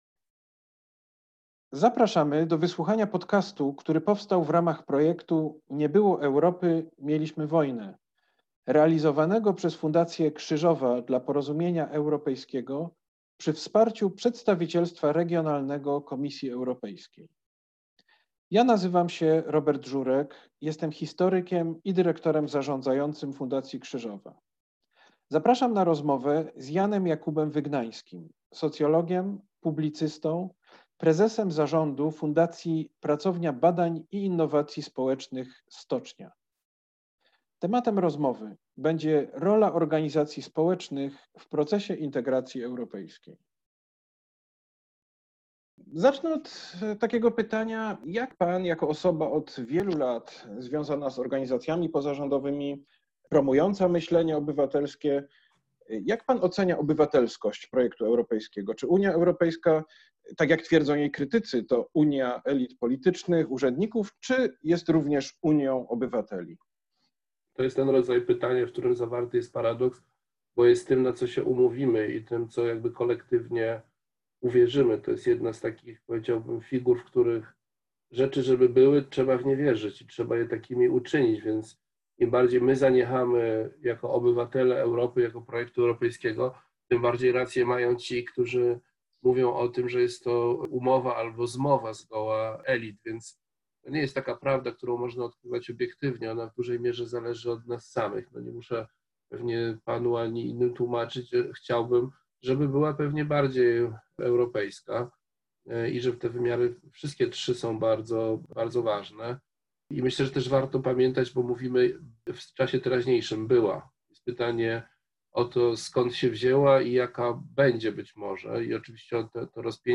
Rozmowa z ekspertem